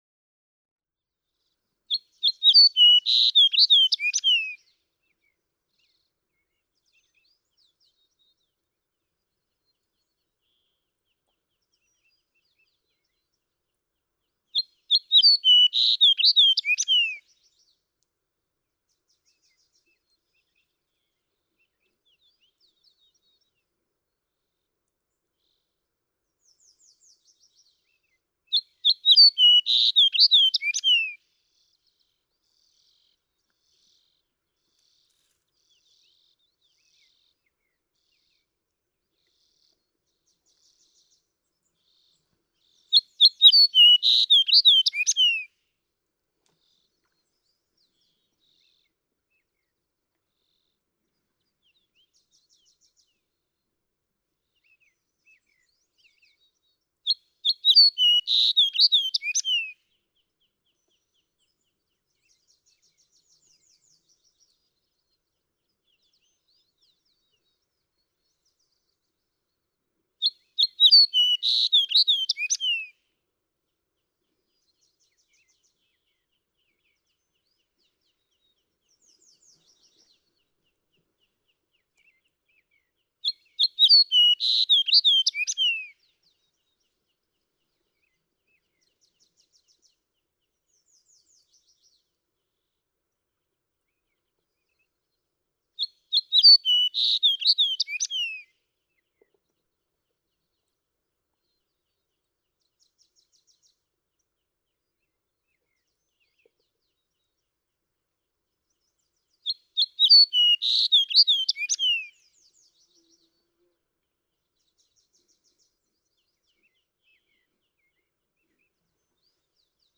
Fox sparrow
283_Fox_Sparrow.mp3